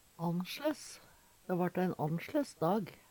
Sjå òg annsjlein (Veggli) Høyr på uttala Ordklasse: Adjektiv Attende til søk